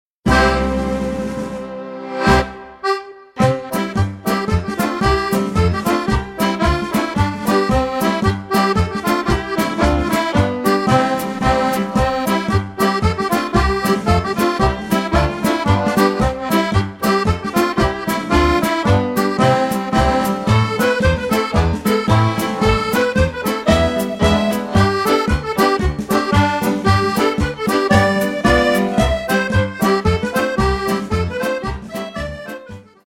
Chriss Cross 8 x 32 Jig Third tune is Jim Anderson’s Delight